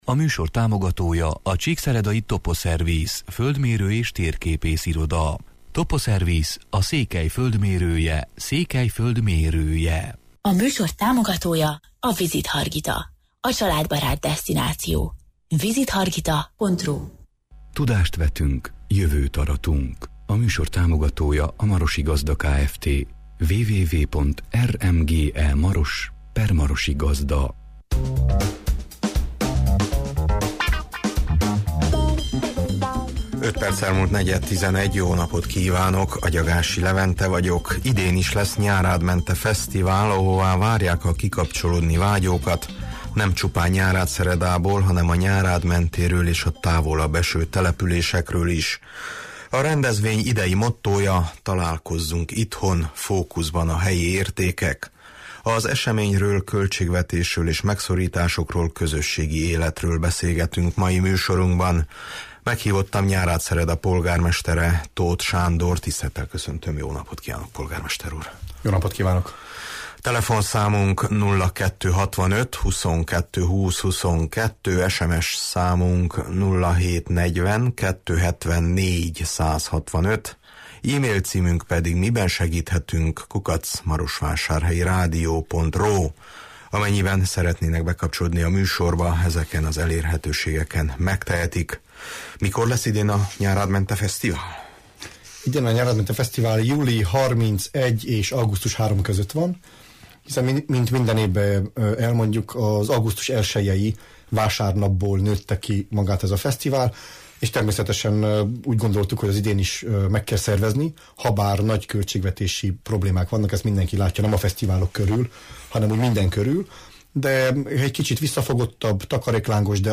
Az eseményről, költségvetésről és megszorításokról, közösségi életről beszélgetünk mai műsorunkban.
Meghívottam Nyárádszereda polgármestere, Tóth Sándor: